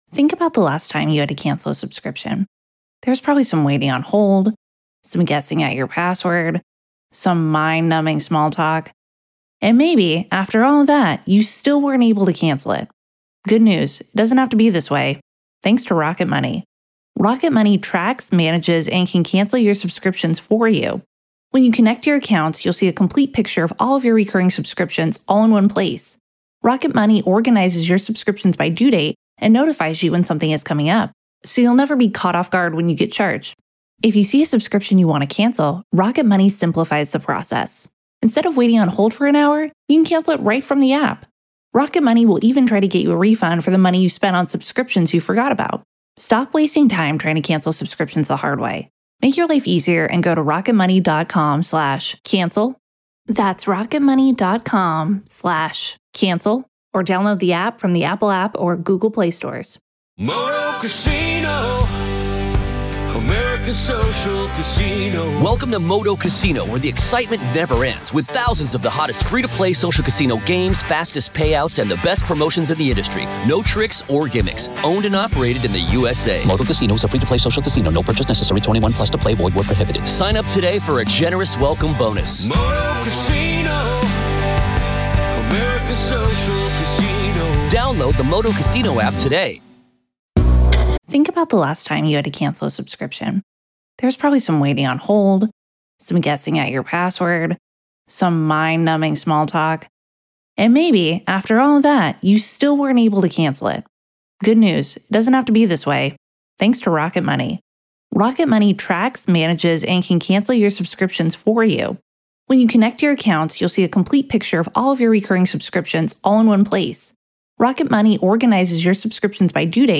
Telles Takes the Stand-Raw Court Audio-NEVADA v. Robert Telles DAY 7 Part 4